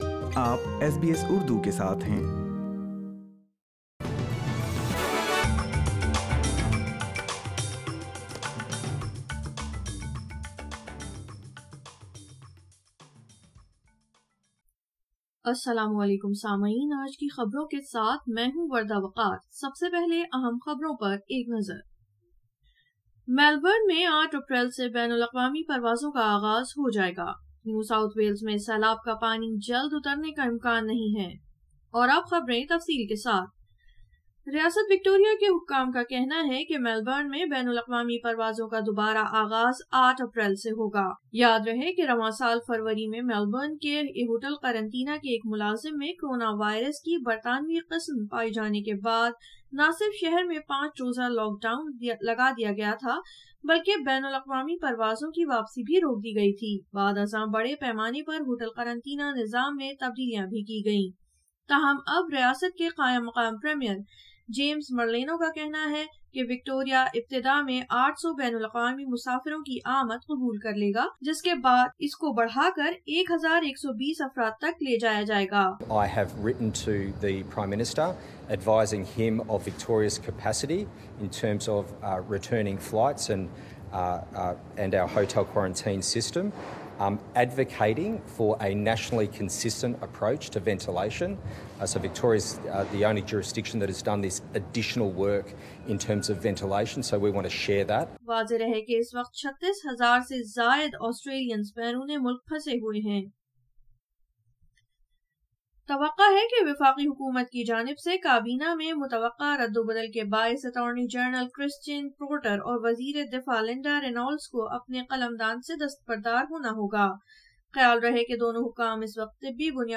اردو خبریں 25 مارچ 2021